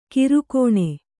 ♪ kirukōṇa